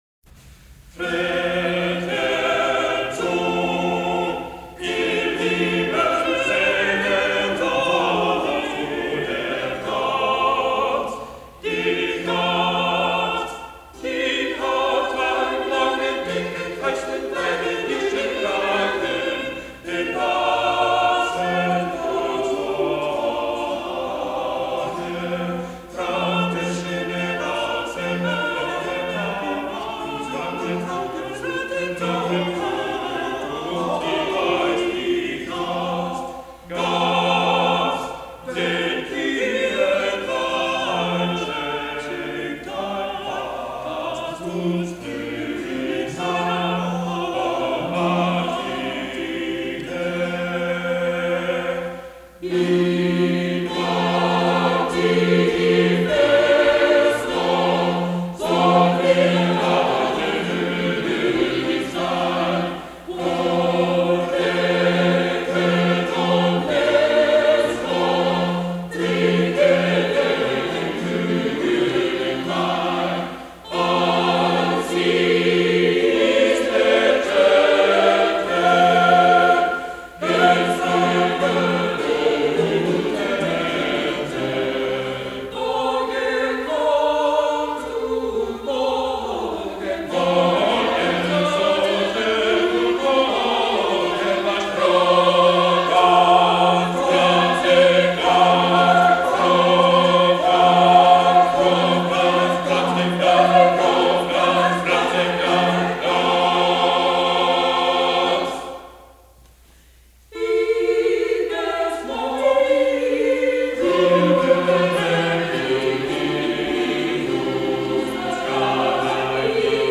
Two more St. Martin’s “goose and wine” songs from Georg Forster’s collection (1540),  The macaronic Latin/German text indicates student origins; lack of musicality is made up in enthusiasm.
Vocal and Instrumental Ens.